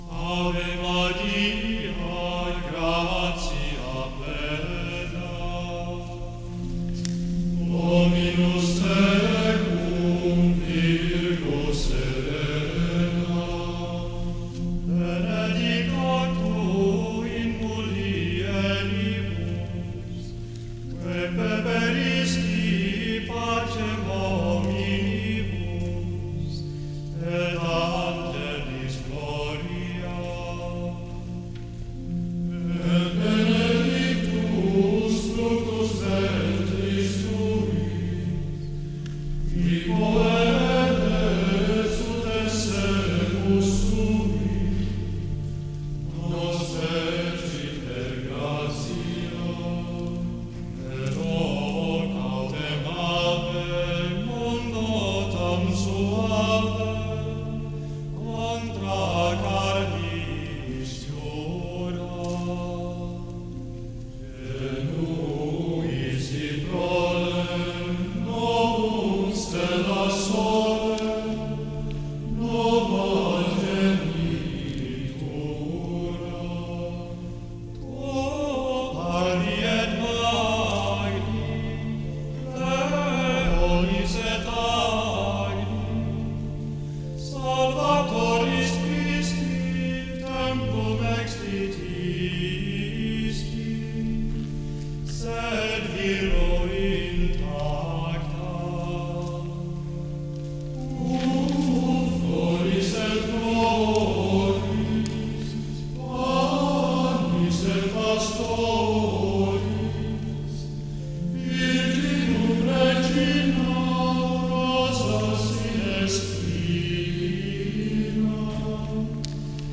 CANTO EM HONRA DE NOSSA SENHORA